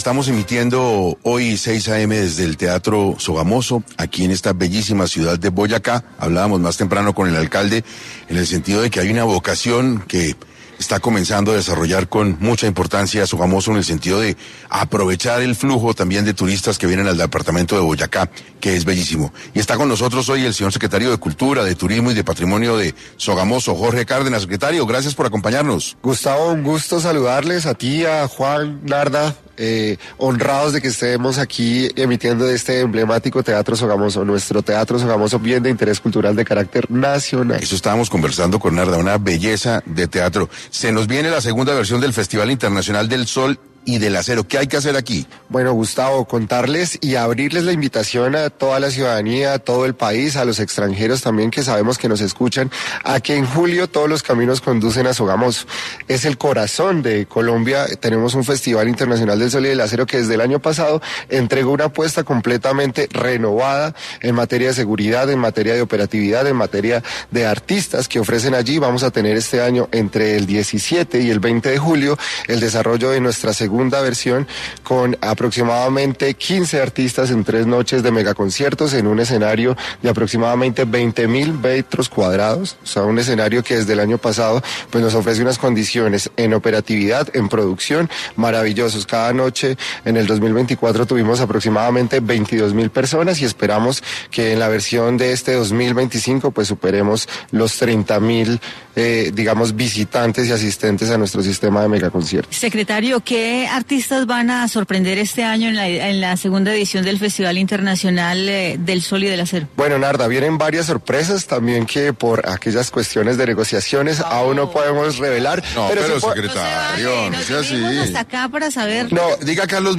Jorge Eliecer Cárdenas, secretario de cultura, turismo y patrimonio de Sogamoso, estuvo en 6AM para hablar del Festival Internacional del Sol y del Acero, en Sogamoso.